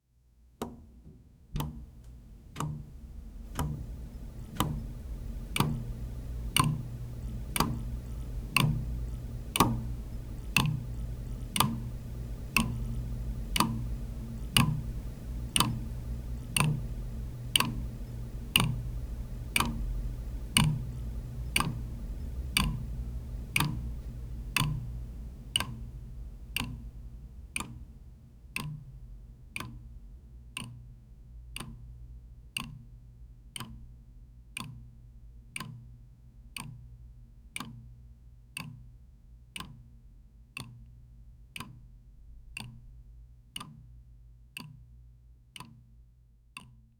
Clock Sound Effects - Free AI Generator & Downloads
Трейлер напряженного фильма. музыки нет, но есть звуковое нагнетание. основной мотив - звук часов с паузами, рисунок выглядит так: туум, тик, тик, тик... туум, тик, тик, тик.
С каждым кругом нагнетание за кадром усиливается
quiet-ticking-of-an-old-7ixbbzj2.wav